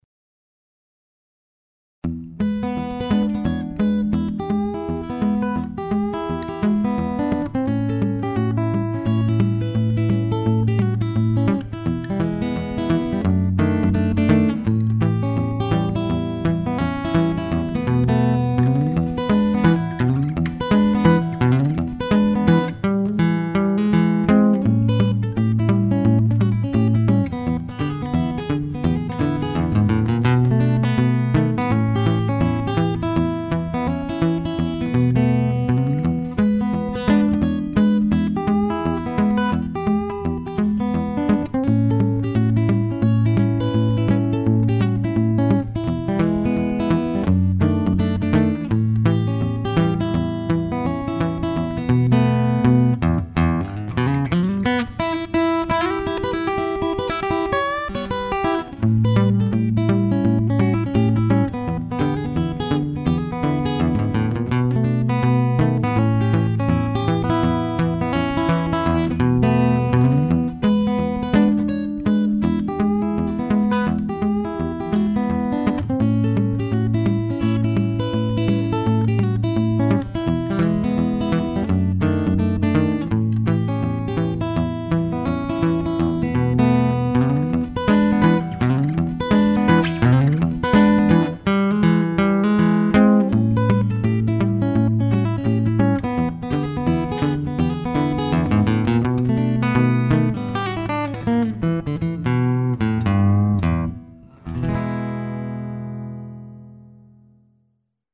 This guy is a very good guitarist and has recorded some songs with this guitar.
The sound of this Adamas is purely amazing!!
The Adamas sounds awful sweet too!